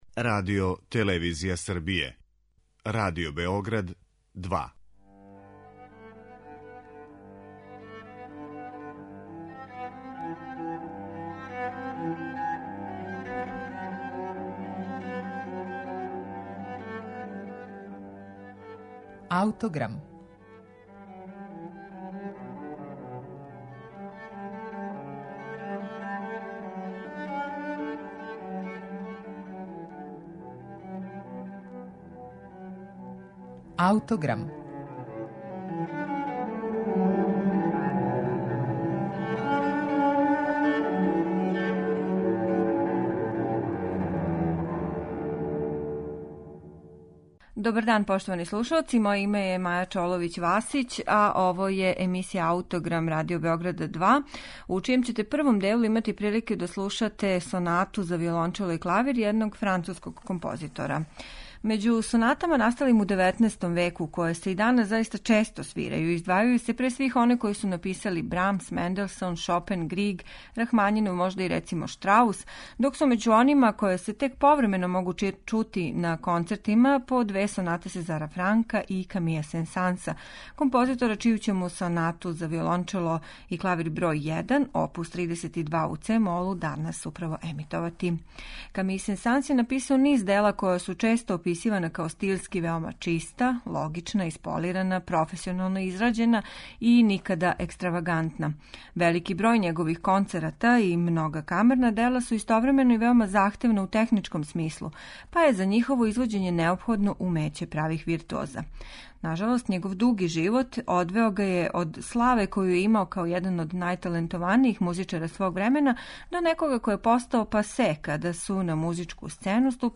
Својеврсна француска прозрачност, као и структурална прецизност и једноставност које уназад воде до Моцарта и Бетовена - то су неке основне одлике стила ове композиције настале 1872. године, у исто време када и концерт за виолончело.